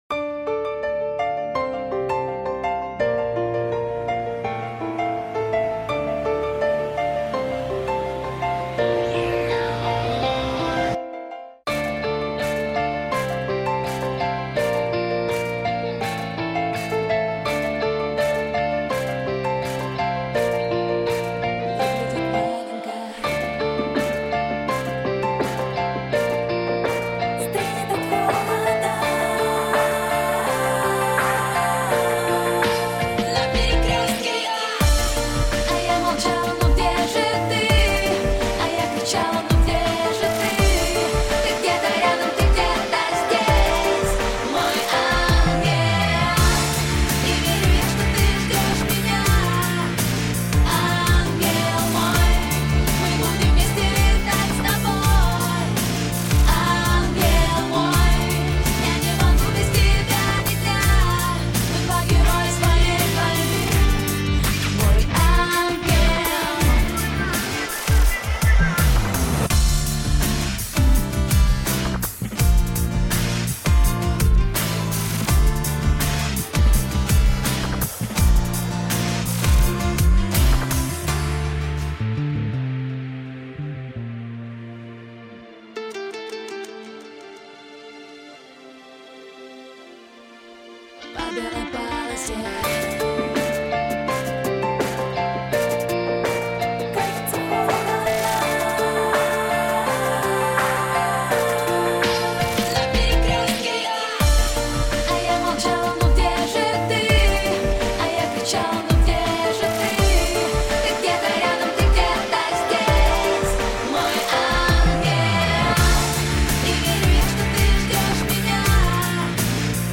Пойте караоке
минусовка версия 233328